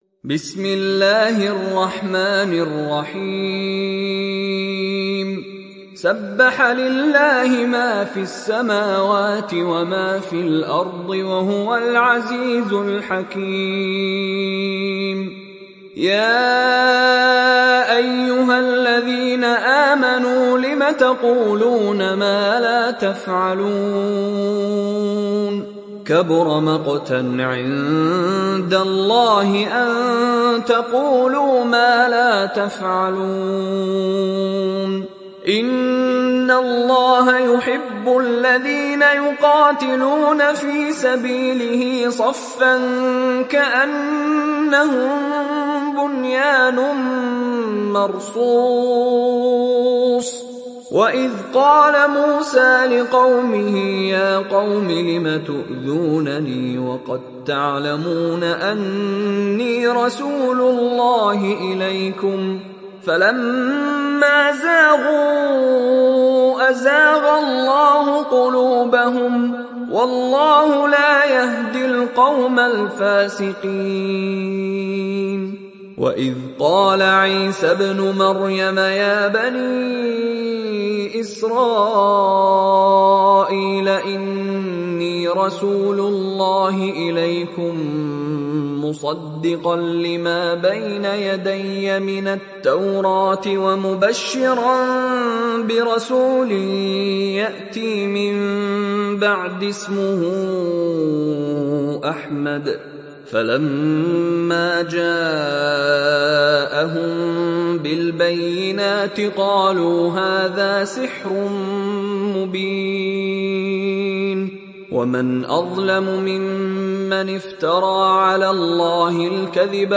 دانلود سوره الصف mp3 مشاري راشد العفاسي (روایت حفص)